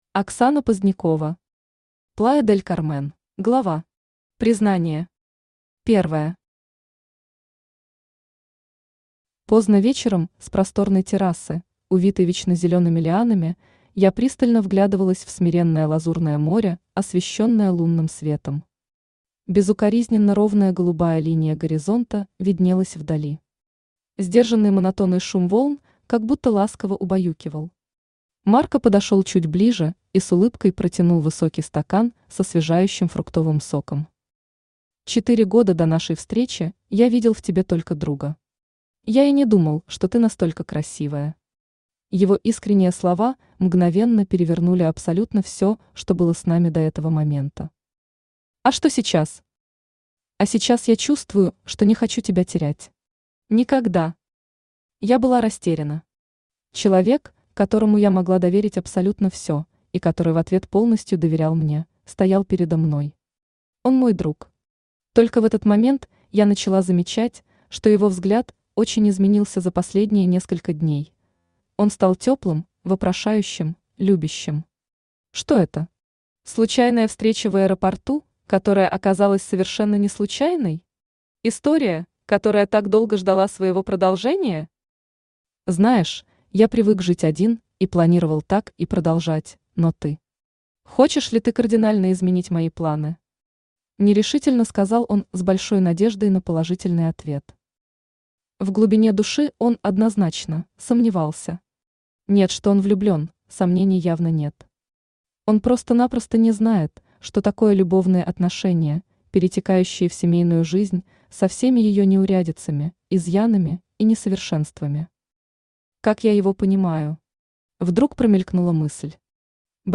Аудиокнига Плая-дель-Кармен | Библиотека аудиокниг
Aудиокнига Плая-дель-Кармен Автор Оксана Позднякова Читает аудиокнигу Авточтец ЛитРес.